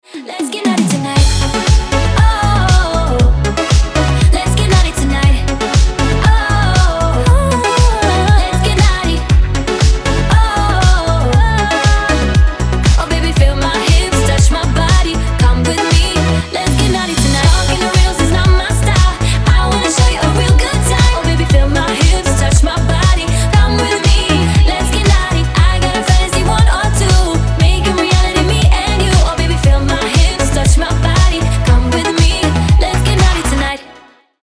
• Качество: 320, Stereo
веселые
заводные
женский голос
Dance Pop
энергичные
Веселый Dance pop